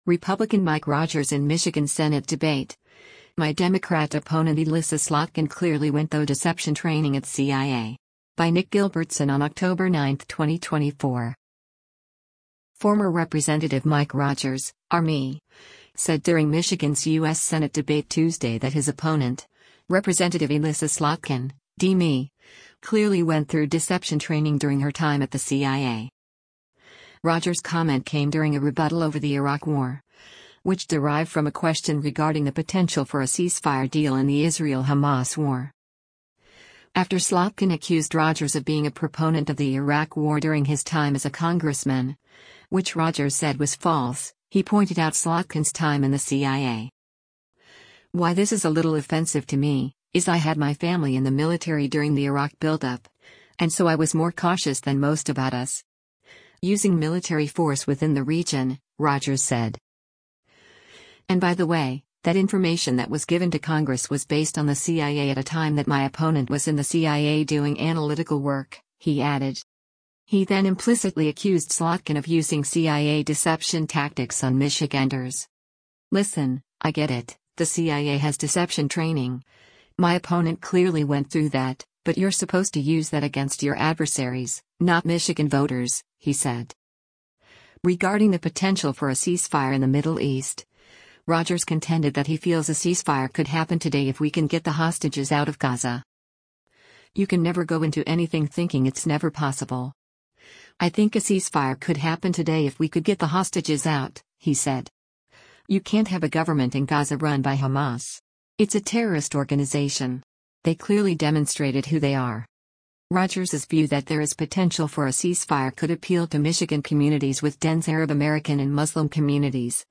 Republican Mike Rogers in Michigan Senate Debate: My Democrat Opponent Elissa Slotkin ‘Clearly Went Though’ Deception Training at CIA
Former Rep. Mike Rogers (R-MI) said during Michigan’s U.S. Senate debate Tuesday that his opponent, Rep. Elissa Slotkin (D-MI), “clearly went through” deception training during her time at the CIA.